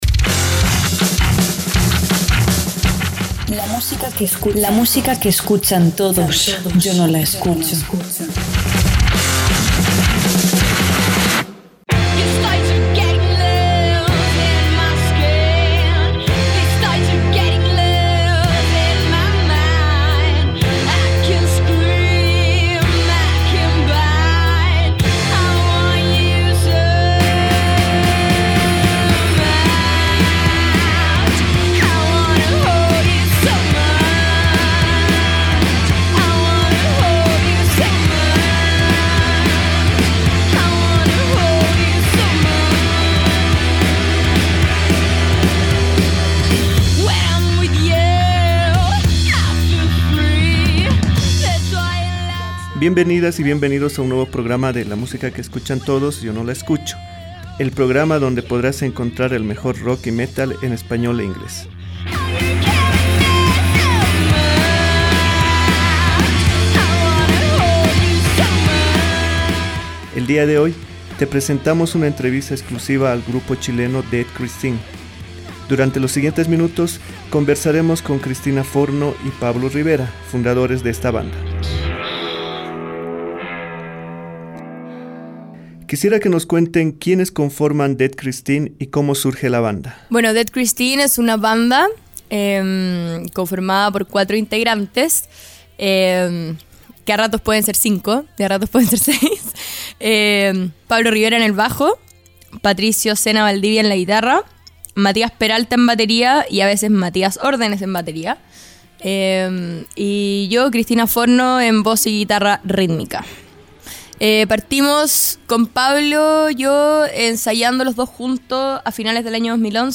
Entrevista Dead Christine | FLACSO Radio